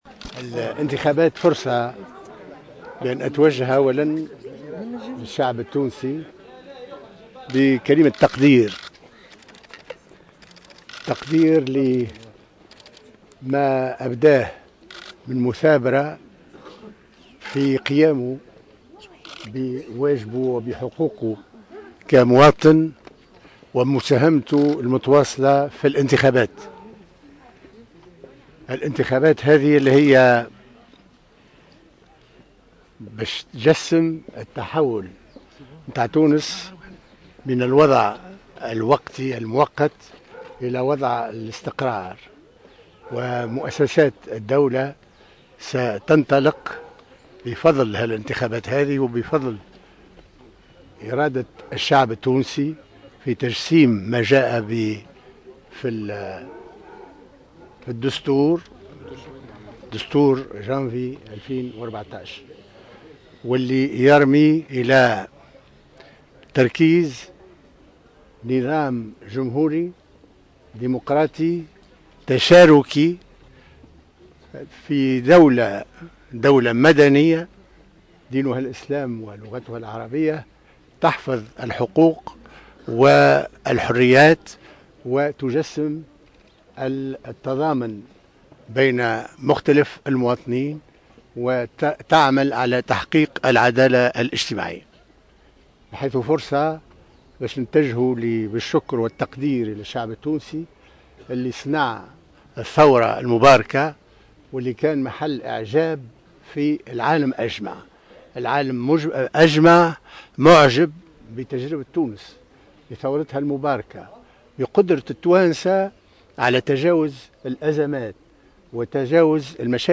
وجّه السيد محمد الناصر رئيس مجلس الشعب بعد أداء واجبه الإنتخابي تحية شكر و تقدير إلى الشعب التونسي على مثابرته و مساهمته المتواصلة في إنجاح الإتنخابات التي ستحول المؤسسات من مؤقتة إلى دائمة في دولة مدنية تحفظ الحقوق و الحريات .